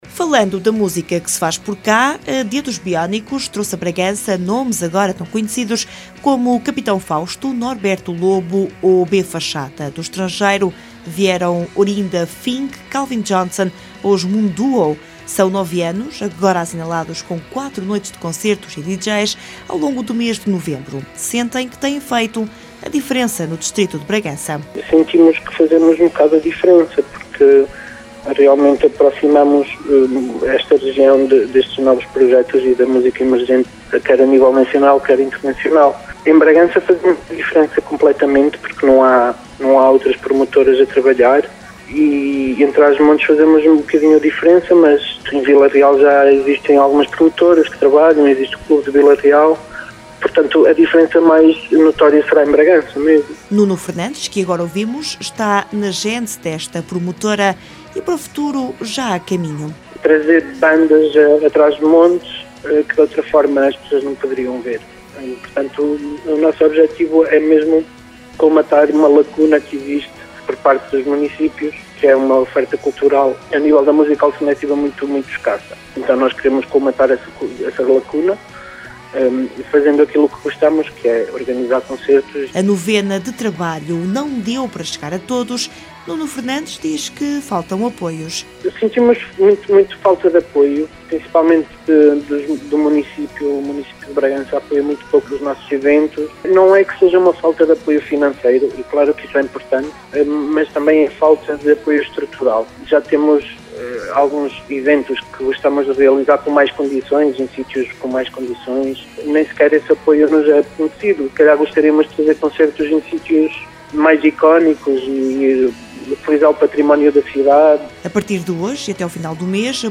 Hoje atuam os Triângulo de Amor Bizarro, uma das maiores bandas indie de Espanha e que ouvíamos de fundo nesta peça.